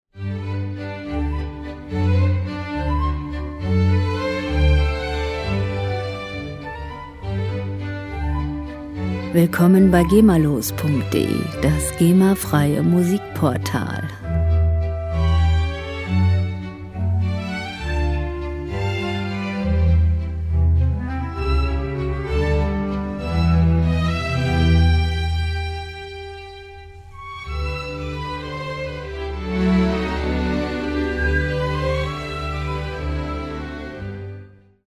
Klassische Musik - Tradition
Musikstil: Operette
Tempo: 203 bpm
Tonart: G-Dur
Charakter: charmant, heiter
Instrumentierung: Sinfonieorchester